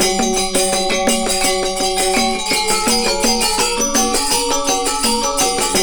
GAMELAN 4.wav